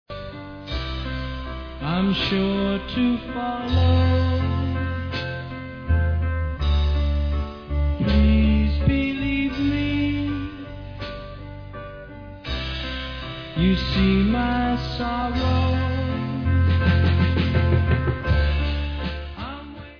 Sixties psychedelia, could be on the "nuggets" box